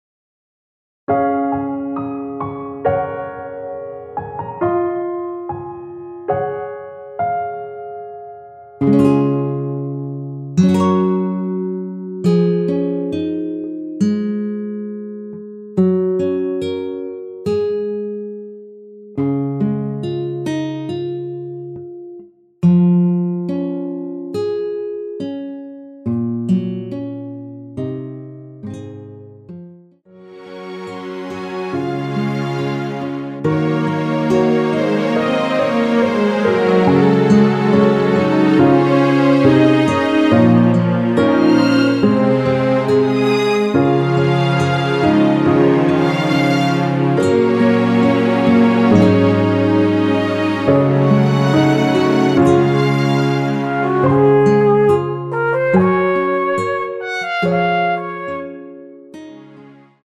여성분이 부르실 수 있는키의 MR입니다.
원키에서(+4)올린 MR입니다.
Db
앞부분30초, 뒷부분30초씩 편집해서 올려 드리고 있습니다.
중간에 음이 끈어지고 다시 나오는 이유는